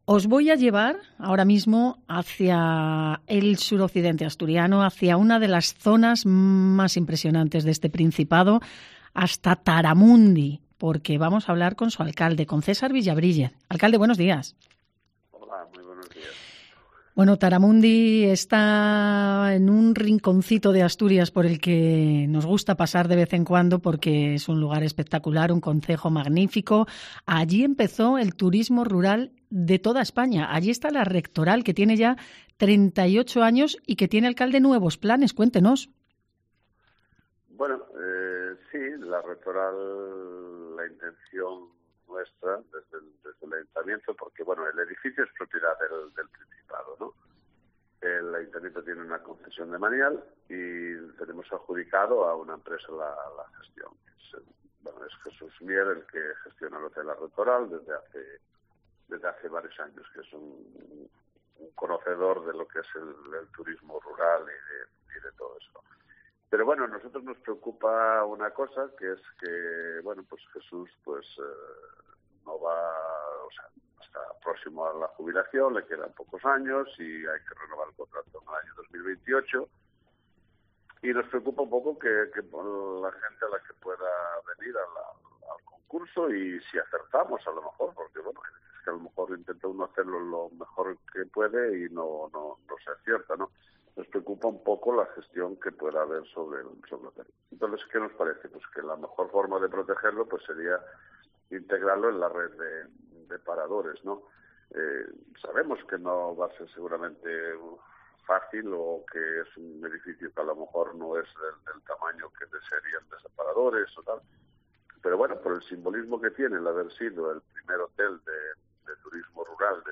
Entrevista al alcalde de Taramundi sobre el futuro de La Rectoral